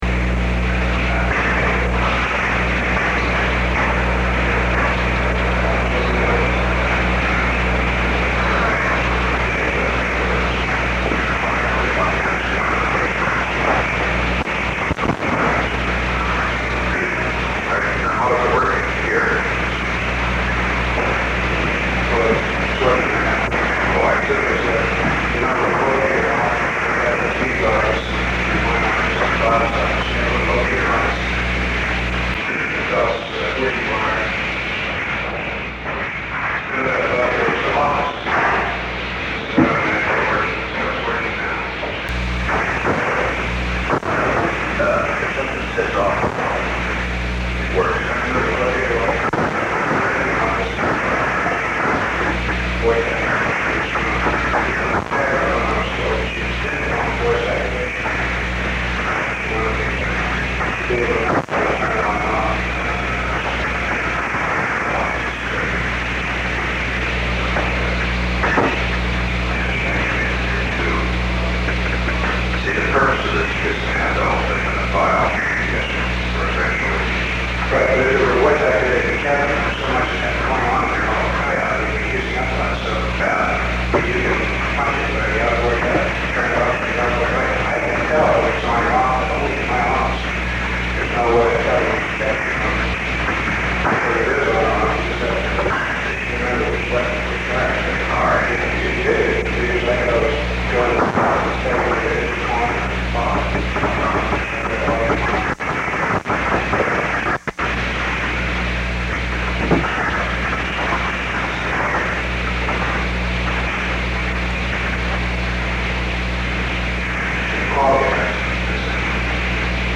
P = President Richard Nixon
APB = Assistant to the President Alexander P. Butterfield